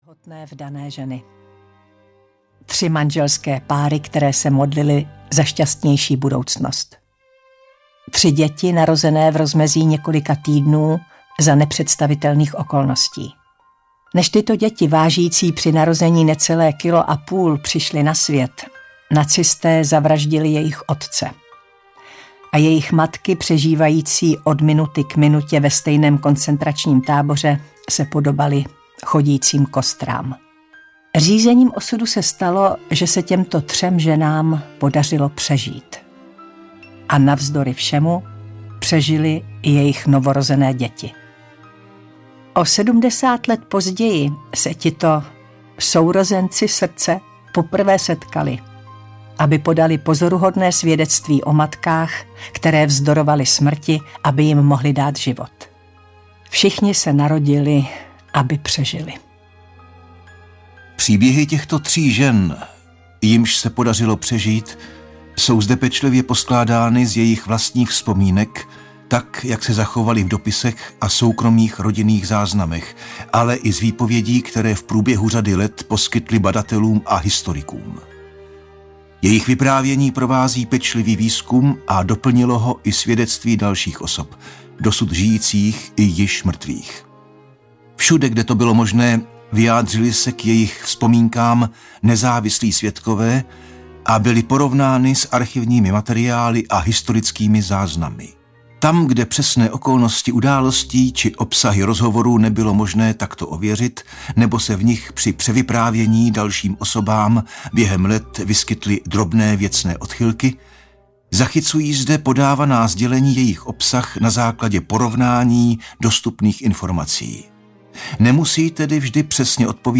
Narodili se, aby přežili audiokniha
Ukázka z knihy
• InterpretVladislav Beneš, Taťjana Medvecká